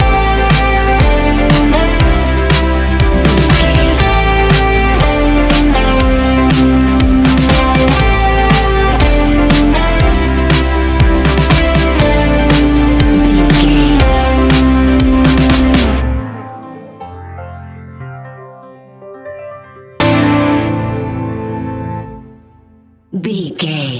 Ionian/Major
ambient
new age
chill out
downtempo
synth
pads